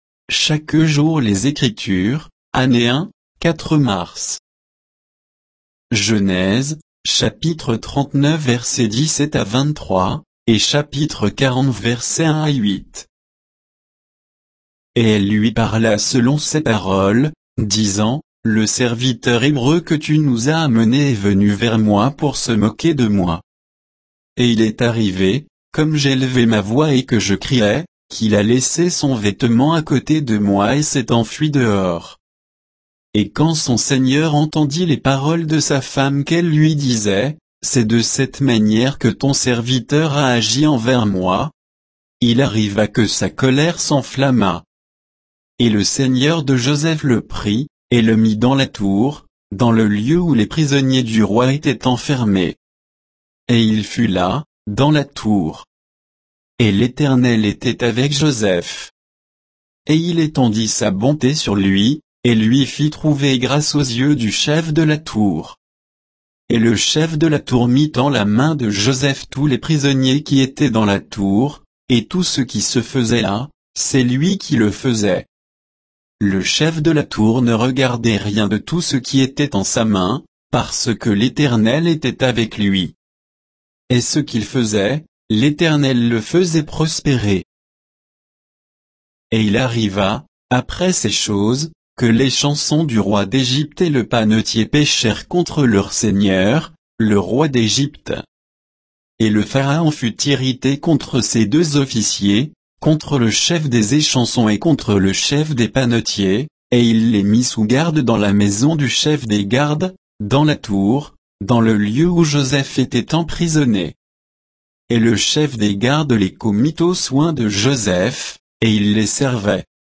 Méditation quoditienne de Chaque jour les Écritures sur Genèse 39